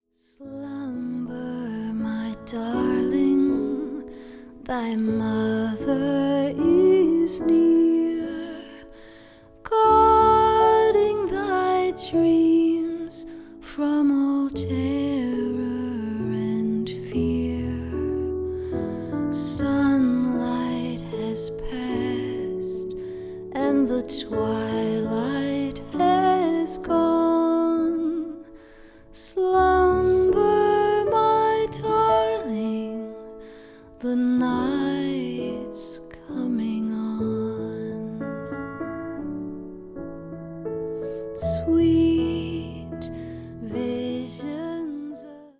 vocal
bass, piano, vibraphones
gutiar
cello